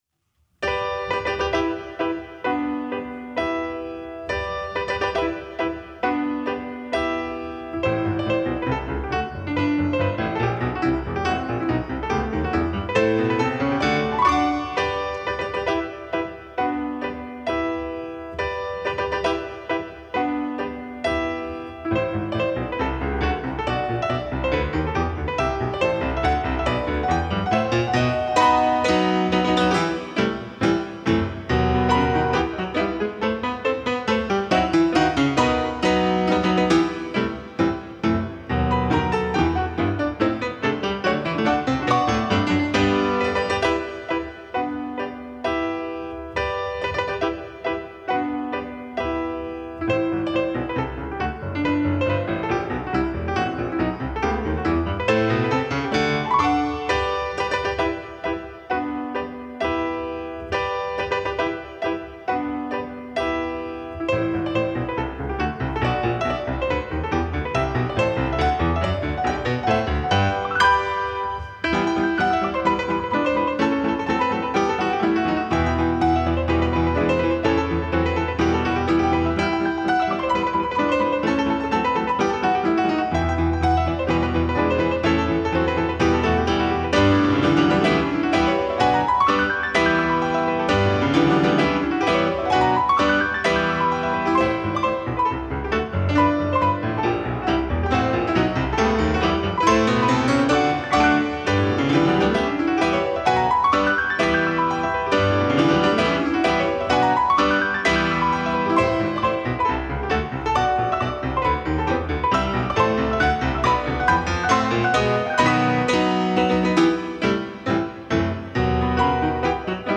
Two pianos
a live recording of a two piano recital
The combined sound of two concert grand pianos requires careful planning.